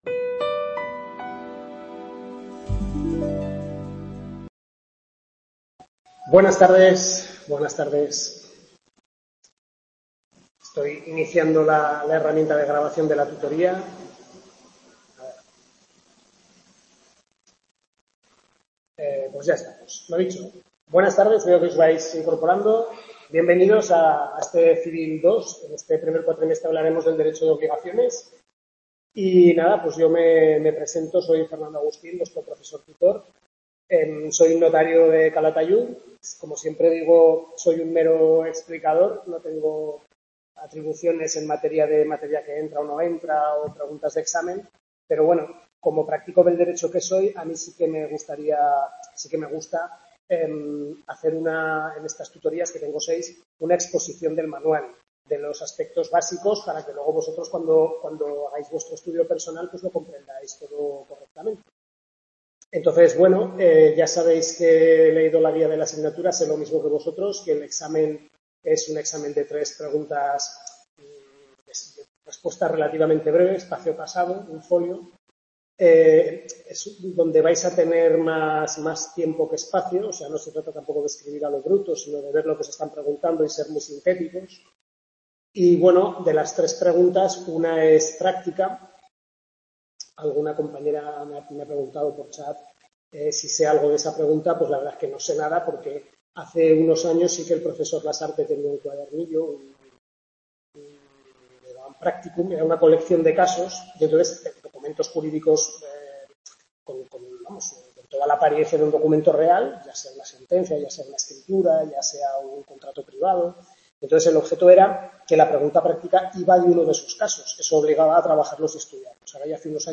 Tutoría 1/6 Civil II primer cuatrimestre.- Centro UNED Calatayud, capítulos 1-4 del Manual del Profesor Lasarte